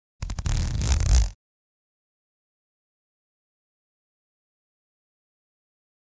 Zipper 7 Sound
household